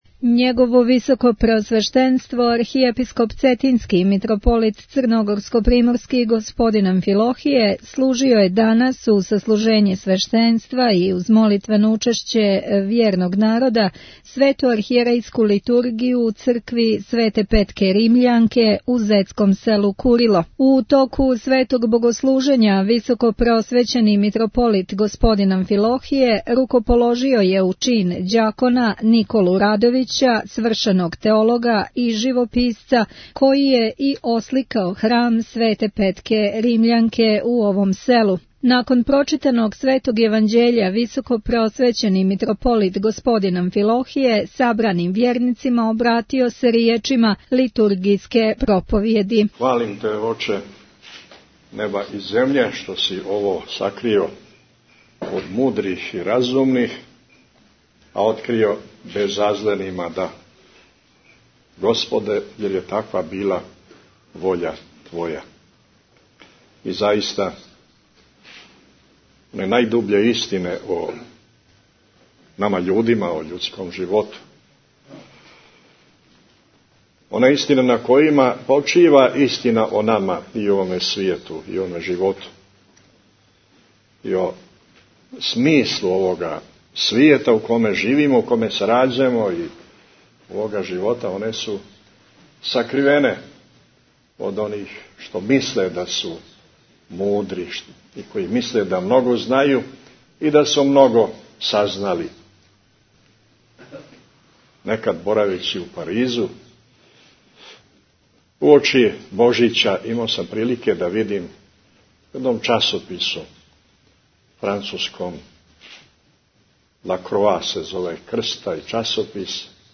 Преузмите аудио датотеку 879 преузимања 399 слушања Митрополит Амфилохије служиo 18. новембра у цркви Свете Петке Римљанке у Курилу у Зети Tagged: Бесједе Download the file . 25:56 минута (4.46 МБ) Његово Високопреосвештенство Архиепископ цетињски Митрополит црногорско - приморски Господин Амфилохије служио је у уторак 18. новембра 2014. године, са свештенством, Свету Архијерејску Литургију у цркви Свете Петке Римљанке у зетском селу Курило. Високопресовећени Митрополит Г. Амфилохије, овом приликом, освештао је камен - темељац будућег манастирског конака, пошто је планирано да на том мјесту буде манастир.